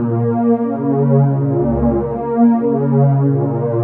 cch_synth_grooved_125_Db.wav